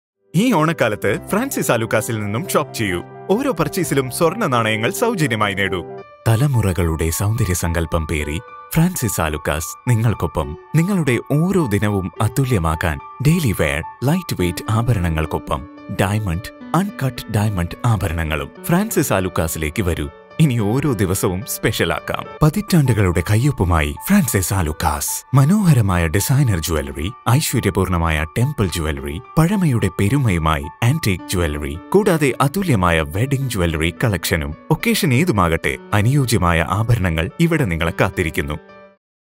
Indian Accent /Neutral English voice with a warm, clear, and versatile tone.
Radio Commercials
Malayalam Voice Male Franalukk